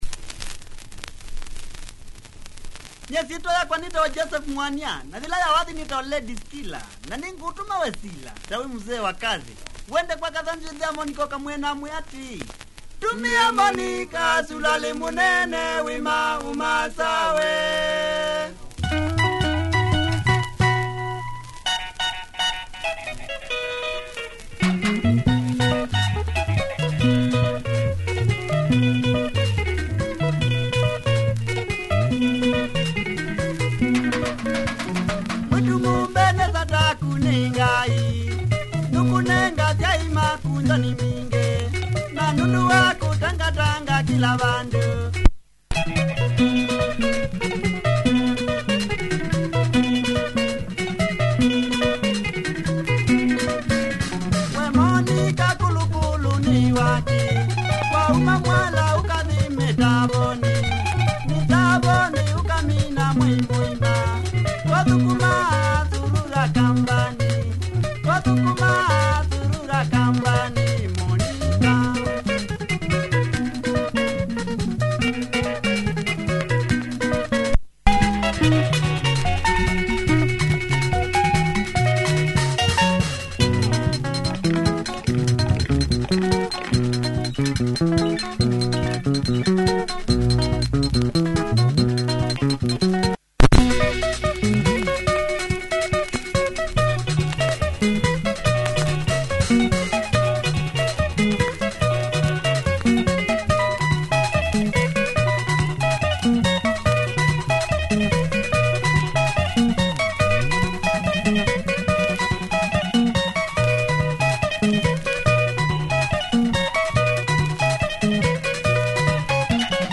Quality Kamba benga mover